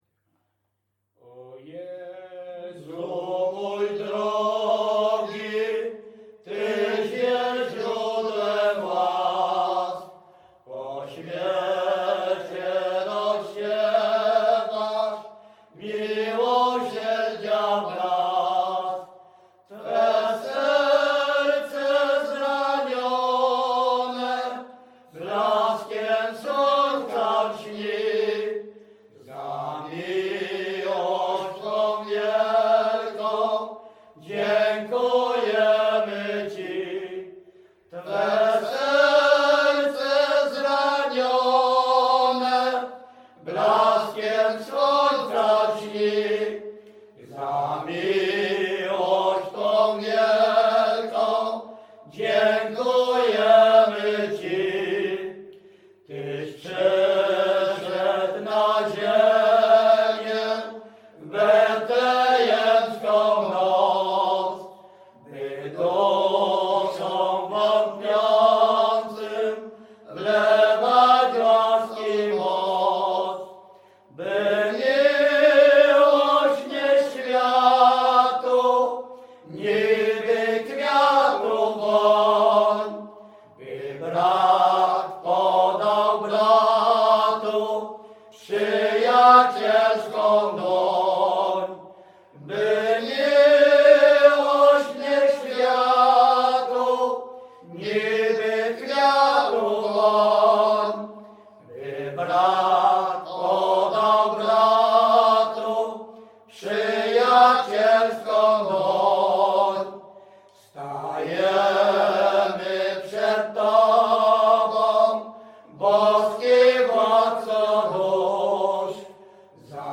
Śpiewacy z Ruszkowa Pierwszego
Wielkopolska, powiat kolski, gmina Kościelec, wieś Ruszków Pierwszy
Nabożna
Array nabożne katolickie pogrzebowe